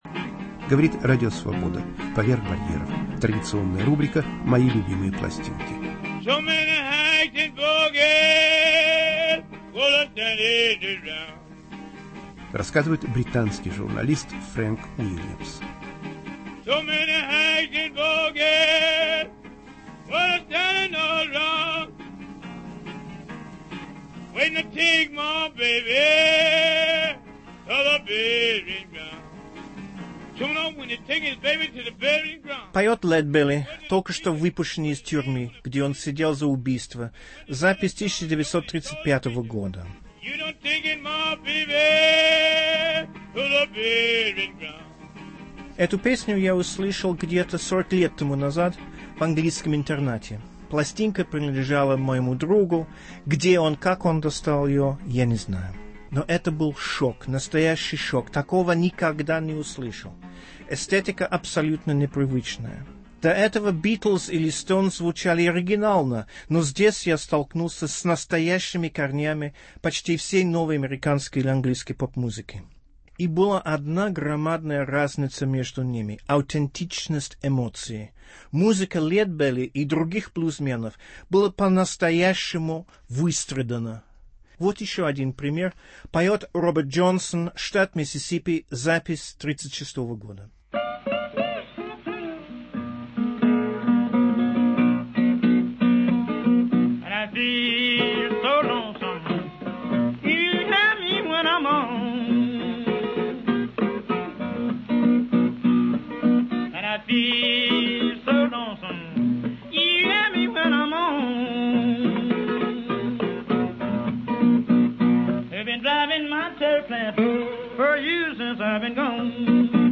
В рубрике "Мои любимые пластинки" - американские каторжные и тюремные песни